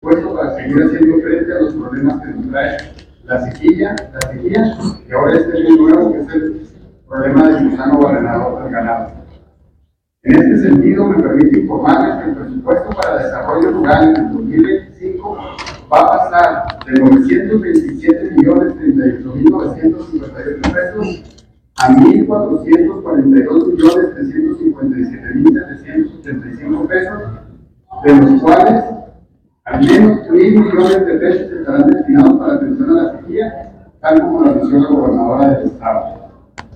AUDIO: JOSÉ DE JESÚS GRANILLO, SECRETARIO DE HACIENDA ESTATAL
Chihuahua, Chih.- Durante el acto protocolario de entrega de propuesta de Presupuesto de Egresos, el secretario de Hacienda, José de Jesús Granillo, informó que como medida de amortiguamiento a fenómenos extraordinarios; climáticos y epidemiológicos al campo chihuahuense, en 2025, el sector rural verá aumentado los recursos dirigidos hacia ese rubro.